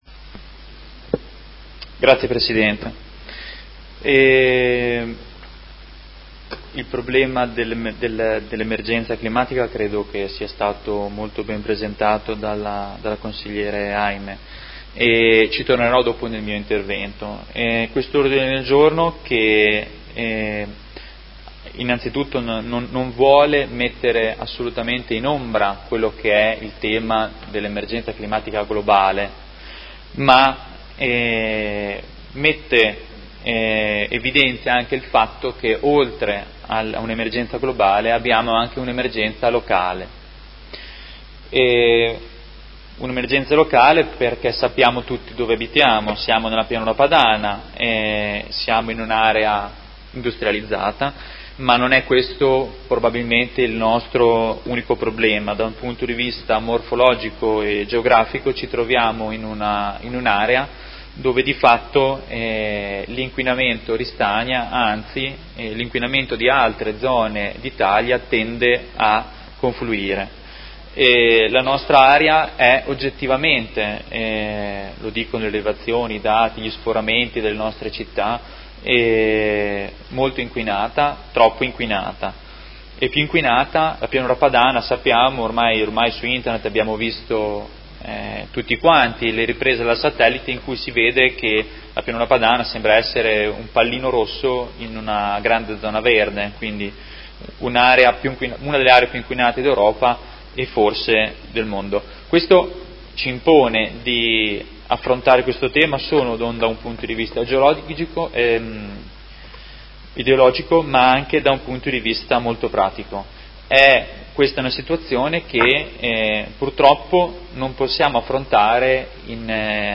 Seduta del 25/07/2019 Presenta Ordine del giorno Prot. Gen. n. 221209